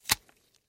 Мякоть ананаса выложили на тарелку